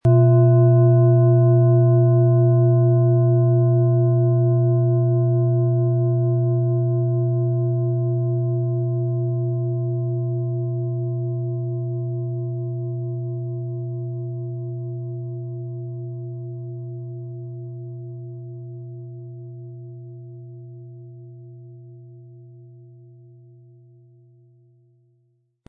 Die Planetenklangschale Lilith ist von Hand gefertigt worden.
Im Lieferumfang enthalten ist ein Schlegel, der die Schale wohlklingend und harmonisch zum Klingen und Schwingen bringt.
MaterialBronze